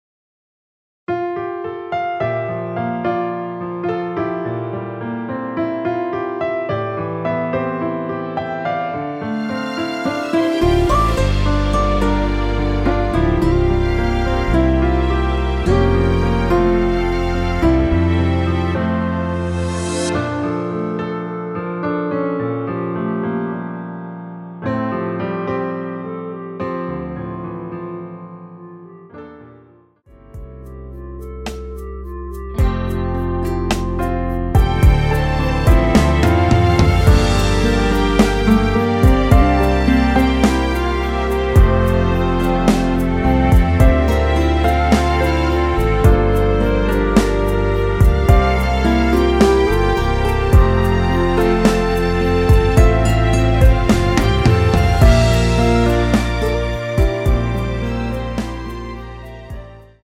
원키 멜로디 포함된 MR입니다.
앞부분30초, 뒷부분30초씩 편집해서 올려 드리고 있습니다.
중간에 음이 끈어지고 다시 나오는 이유는
(멜로디 MR)은 가이드 멜로디가 포함된 MR 입니다.